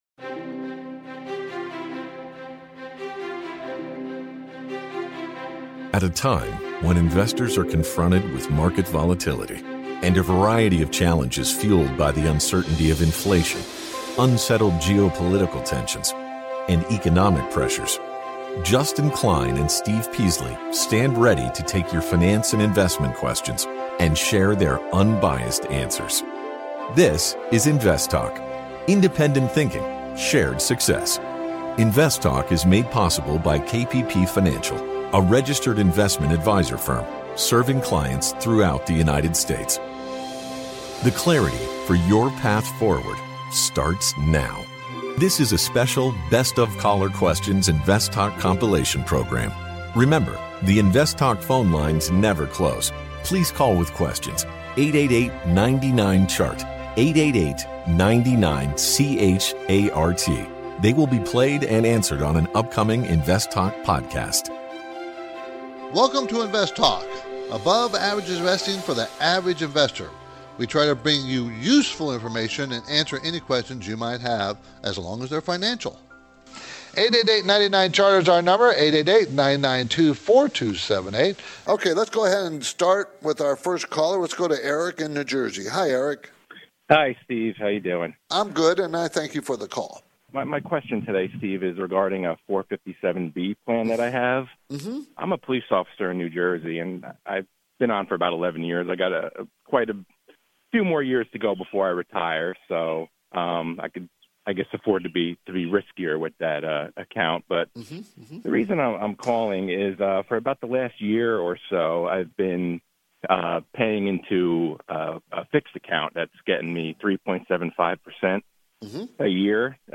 field a variety of finance and investment questions from callers across the United States and around the world